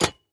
Minecraft Version Minecraft Version latest Latest Release | Latest Snapshot latest / assets / minecraft / sounds / block / copper_trapdoor / toggle1.ogg Compare With Compare With Latest Release | Latest Snapshot
toggle1.ogg